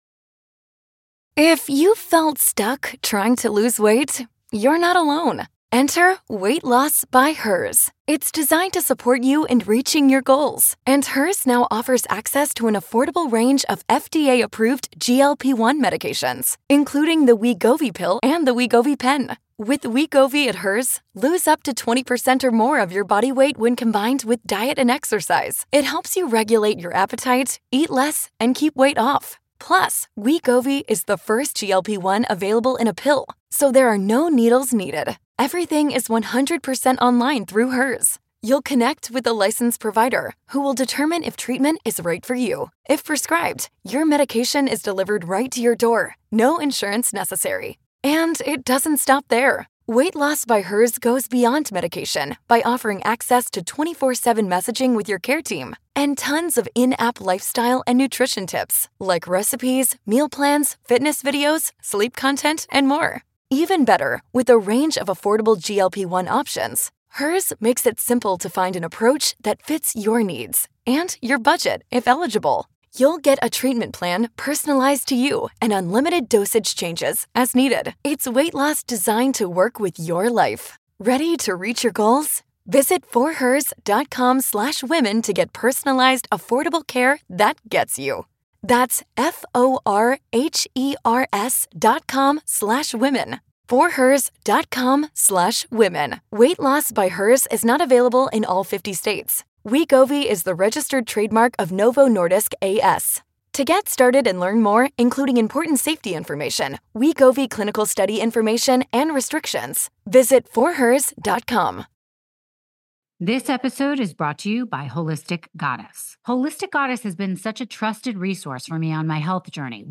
Tune in for a conversation packed with practical advice and empowerment.